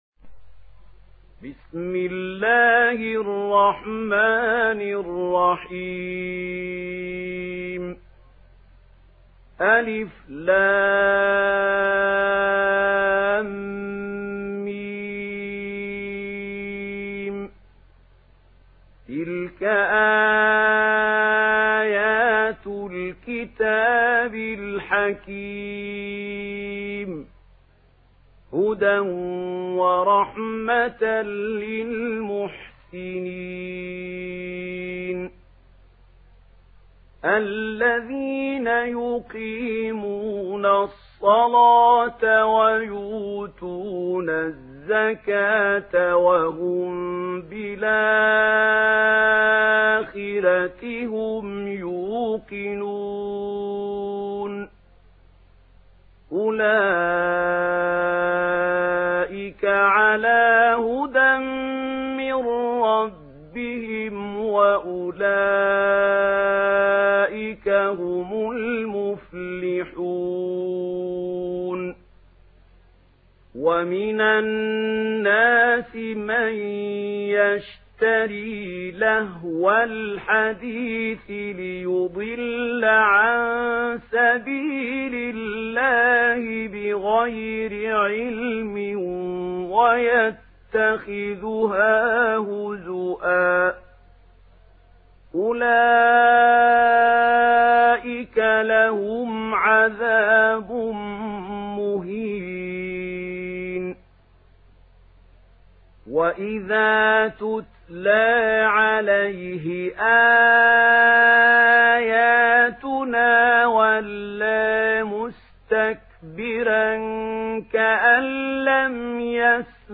Surah Lokman MP3 in the Voice of Mahmoud Khalil Al-Hussary in Warsh Narration
Listen and download the full recitation in MP3 format via direct and fast links in multiple qualities to your mobile phone.
Murattal Warsh An Nafi